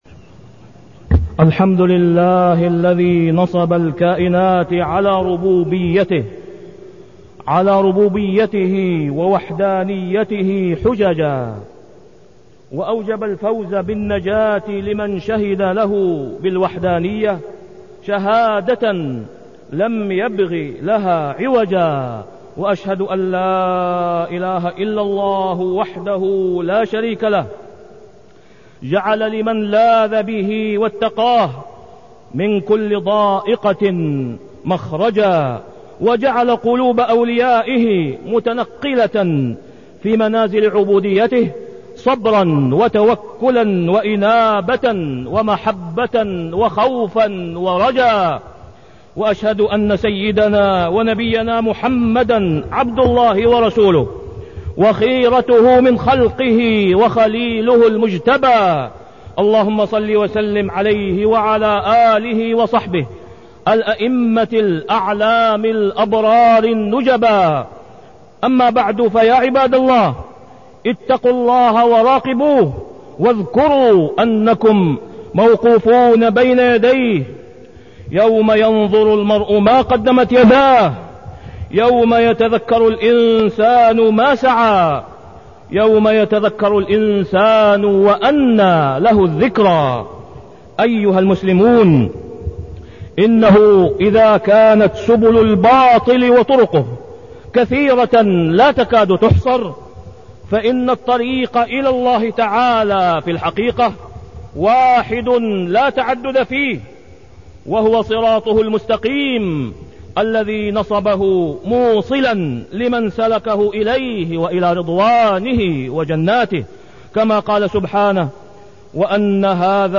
تاريخ النشر ٨ رجب ١٤٢٤ هـ المكان: المسجد الحرام الشيخ: فضيلة الشيخ د. أسامة بن عبدالله خياط فضيلة الشيخ د. أسامة بن عبدالله خياط الطريق إلى الله